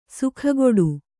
♪ sukhagoḍu